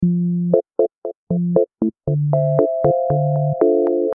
Smooth Electro-Ambient Bossa Nova Music Loop – 117 BPM
Experience smooth rhythms and atmospheric textures in this Electro-Ambient Bossa Nova music loop at 117 BPM. Perfect for videos, commercials, and background music, this loop adds a relaxing and sophisticated vibe.
Genres: Synth Loops
Tempo: 117 bpm
Smooth-electro-ambient-bossa-nova-music-loop–117-bpm.mp3